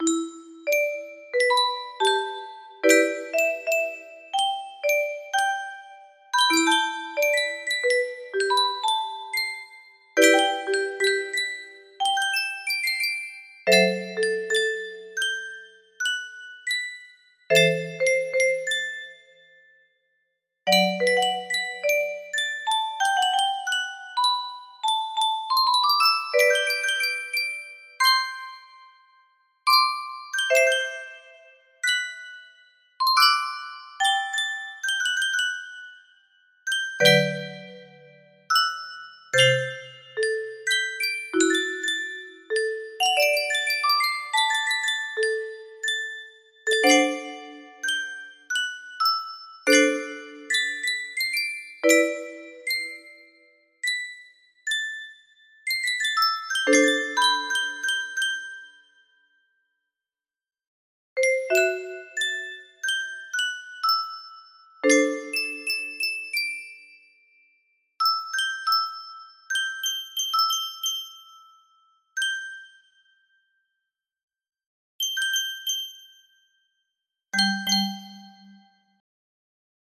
For Tuxedo music box melody
Full range 60